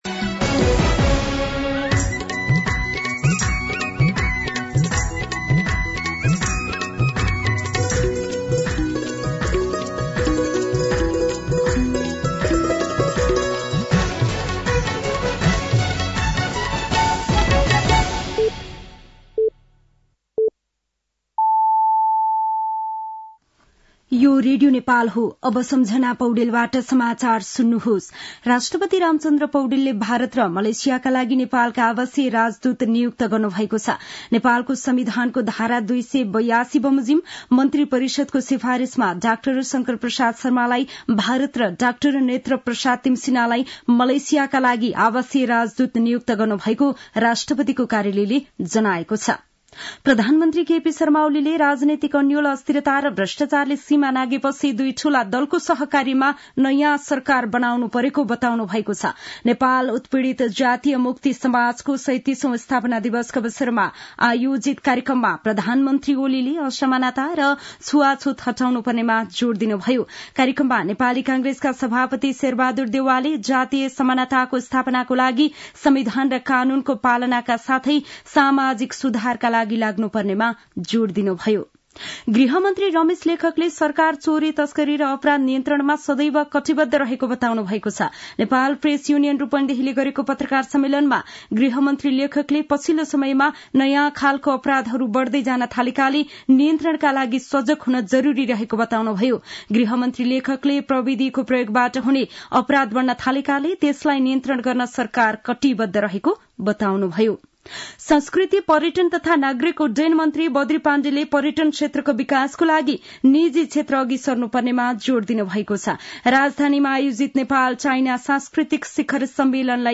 साँझ ५ बजेको नेपाली समाचार : ४ पुष , २०८१